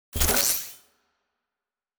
mixkit-screechy-electified-item-3213.wav